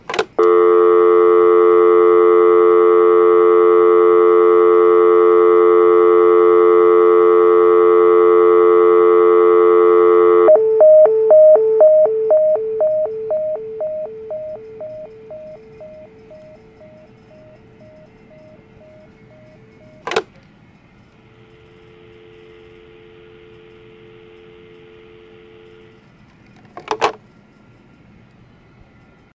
telephone
answer dial-tone dialtone drop hang hello hook no sound effect free sound royalty free Music